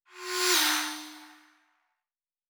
pgs/Assets/Audio/Sci-Fi Sounds/Movement/Fly By 02_4.wav at 7452e70b8c5ad2f7daae623e1a952eb18c9caab4
Fly By 02_4.wav